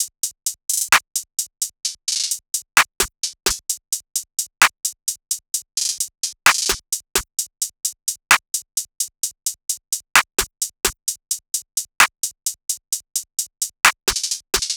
SOUTHSIDE_beat_loop_grey_top_02_130.wav